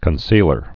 (kən-sēlər)